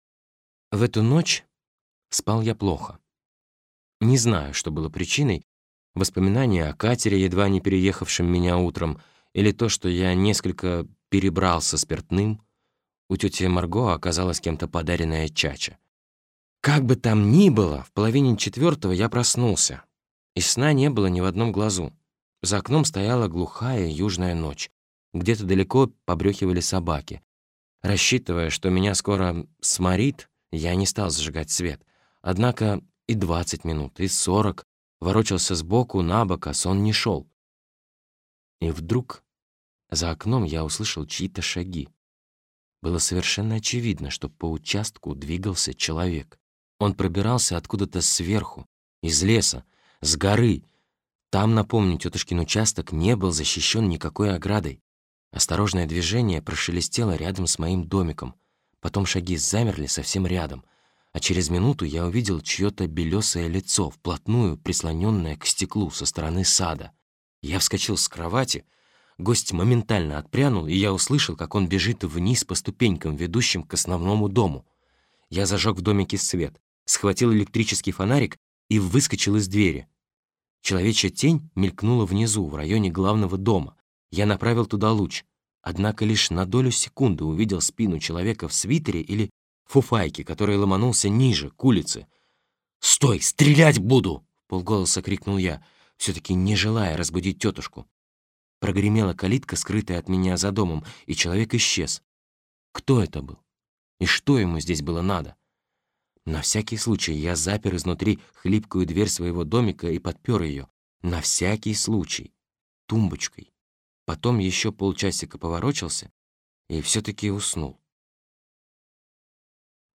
Аудиокнига Горький инжир | Библиотека аудиокниг
Прослушать и бесплатно скачать фрагмент аудиокниги